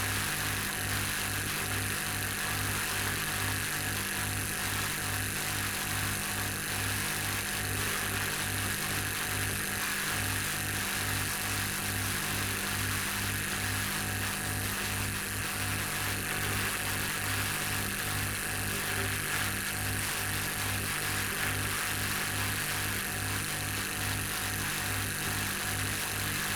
pgs/Assets/Audio/Sci-Fi Sounds/Hum and Ambience/Hum Loop 9.wav at master
Hum Loop 9.wav